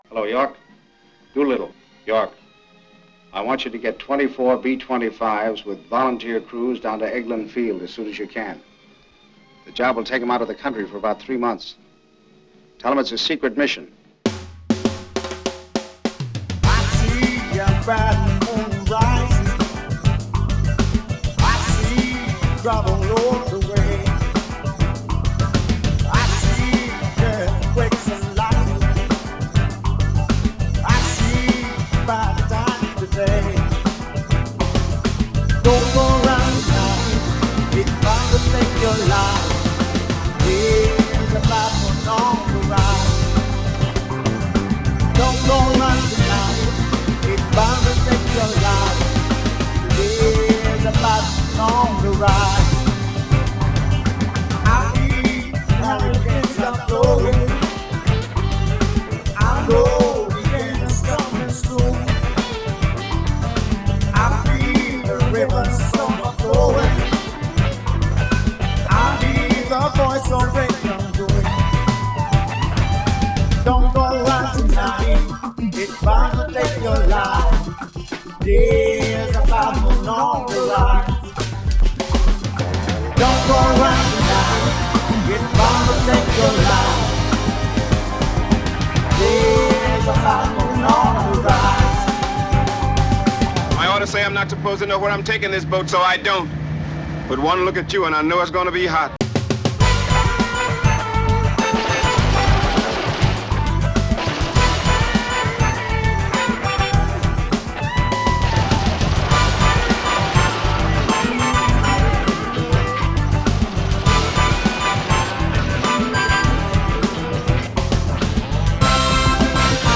cover version